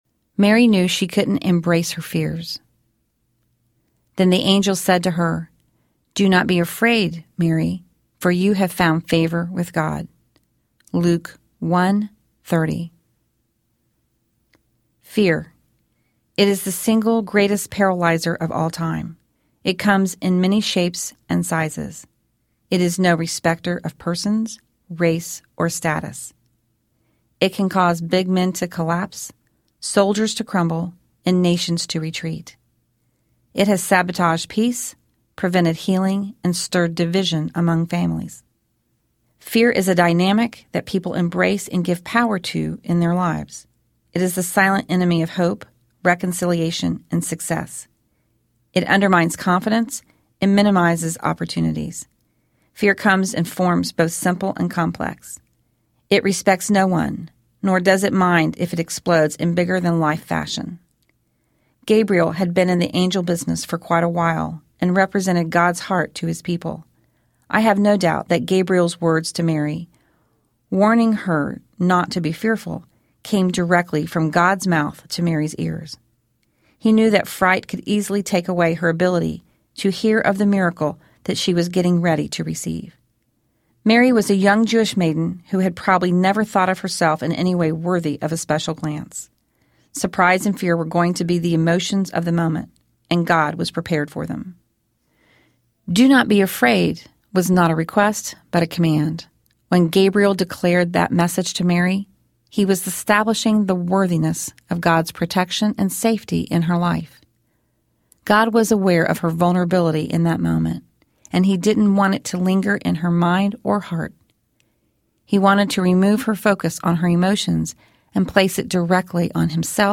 Heaven in Her Arms Audiobook